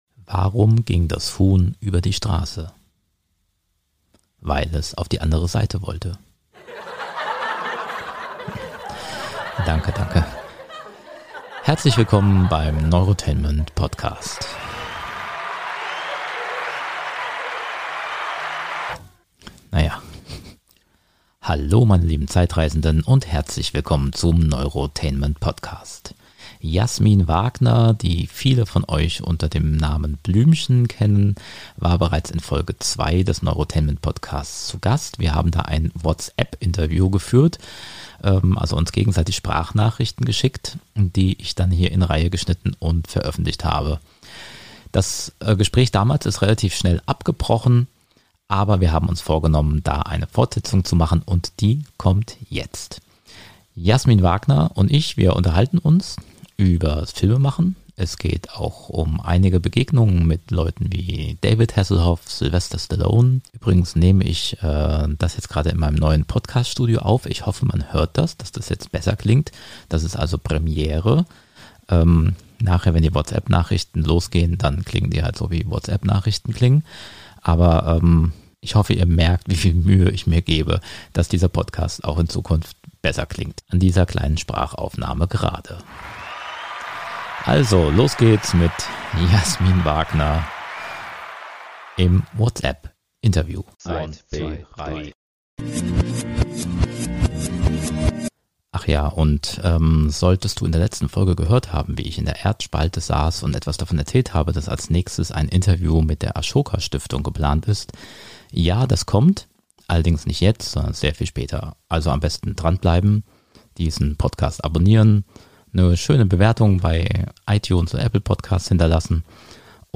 Eine alte Folge der Neurotainment Show zum Wieder-Entdecken: Jasmin Wagner im Whatsapp-Interview. Sie erzählt über ihr Comeback als Blümchen während der Corona-Krise, Backen als Leidenschaft und ihren Karriere-Wandel zur Schauspielerin.